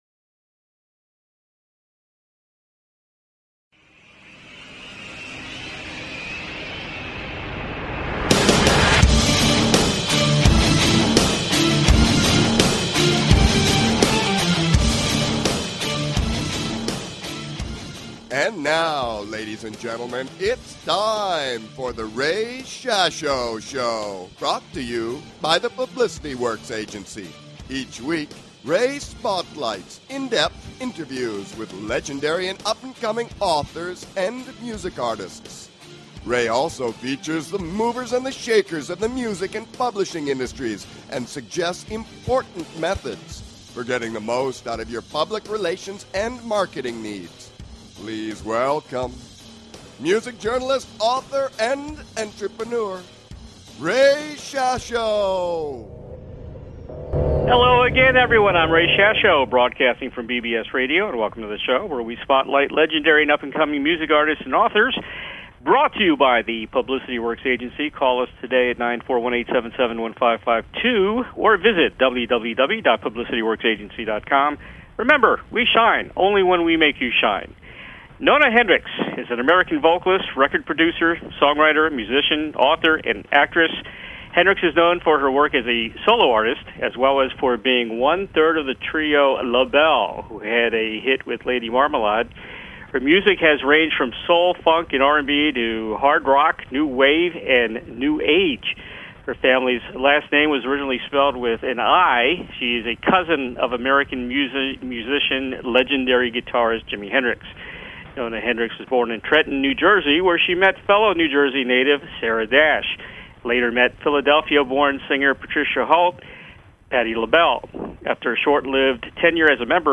Guest, Nona Hendryx